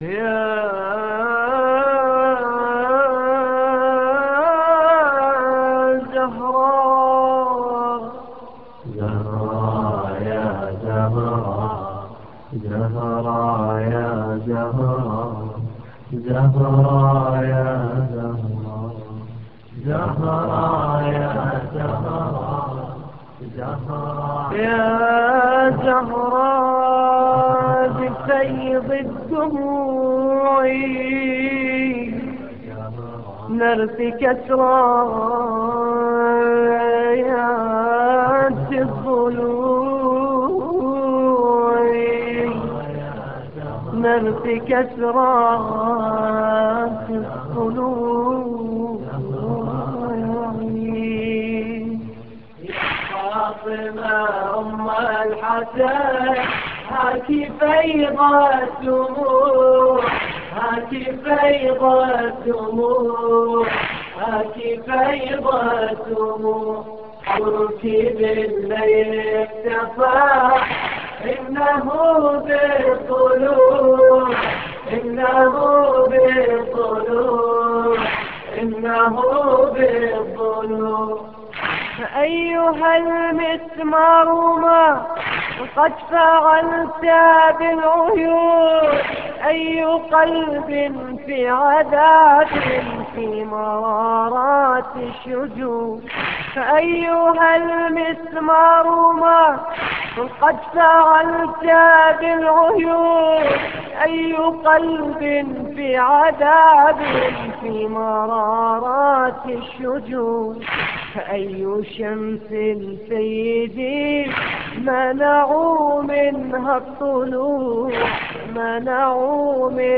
لطميات متنوعة
(استديو)